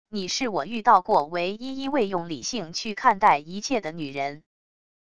你是我遇到过唯一一位用理性去看待一切的女人wav音频生成系统WAV Audio Player